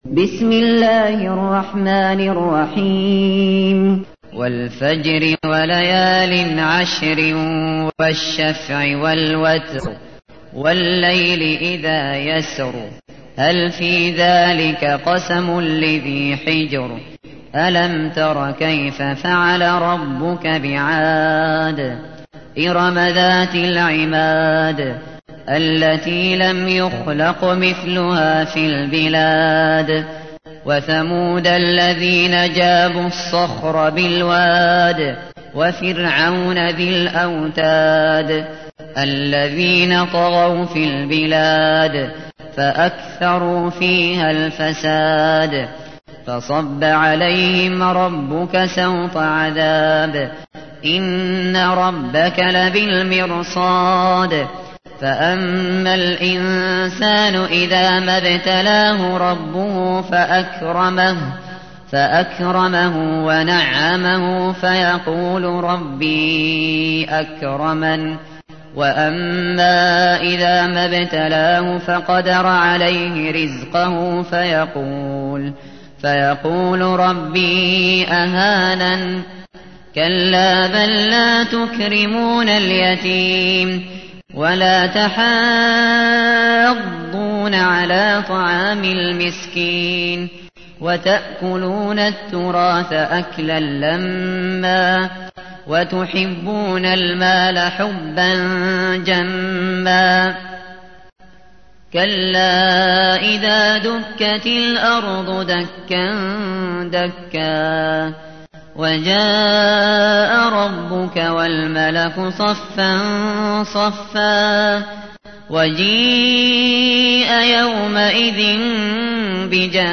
تحميل : 89. سورة الفجر / القارئ الشاطري / القرآن الكريم / موقع يا حسين